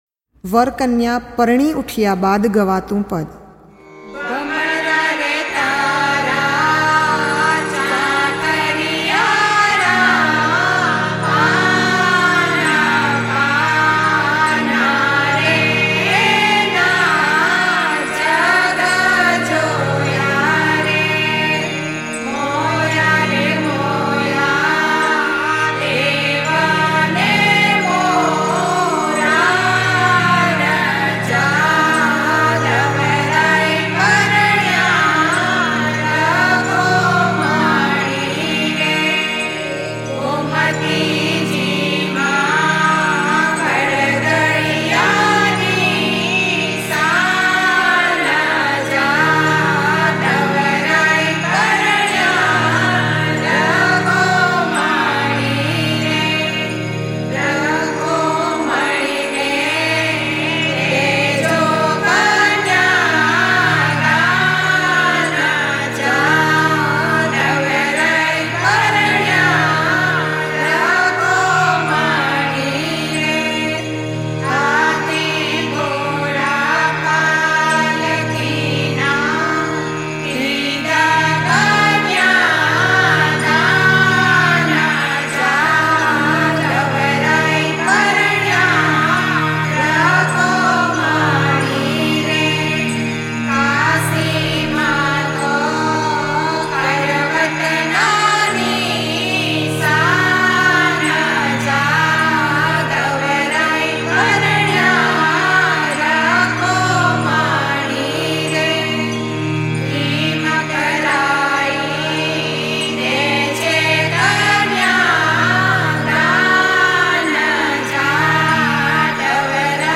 વર કન્યા પરણી ઉઠ્યા બાદ ગવાતું પદ ...ભમરા રે તારા ચાકરિયારા ...